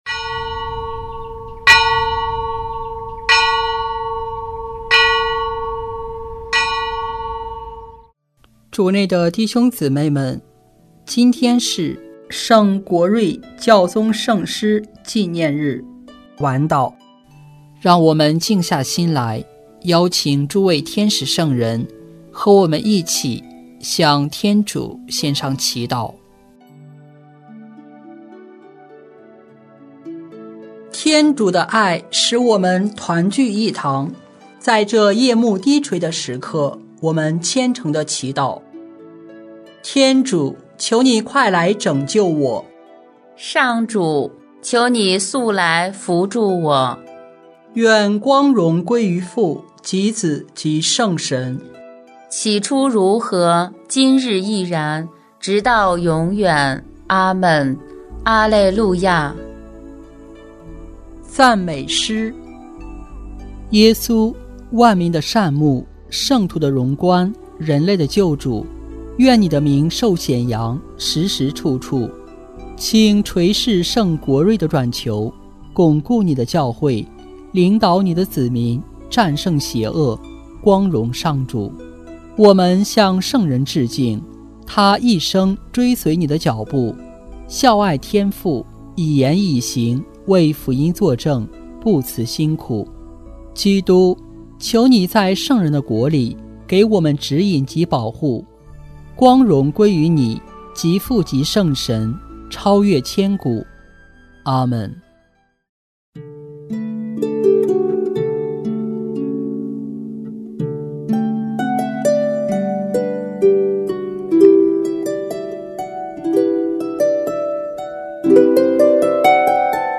圣咏吟唱 对经一 ：你们不能侍奉天主，而又侍奉金钱。